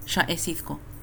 Pronunciation of shà-ésiþko